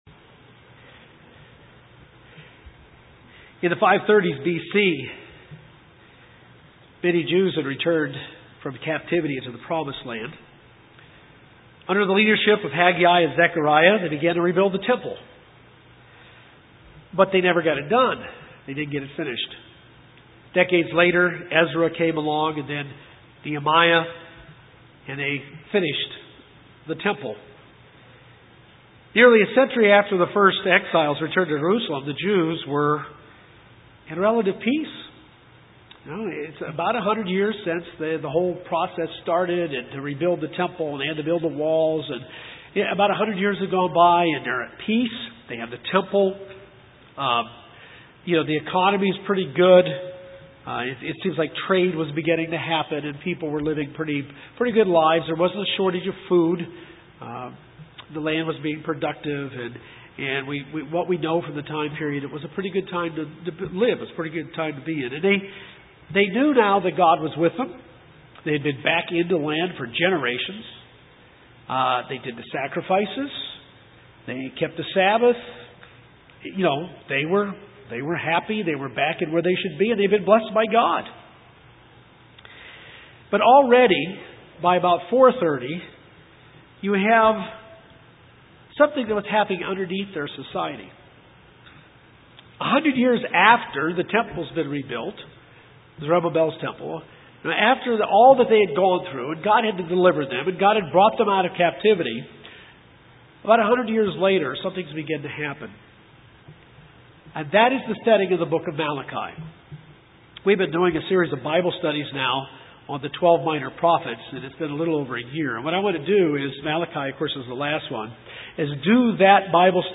This sermon takes a look at the book of Malachi.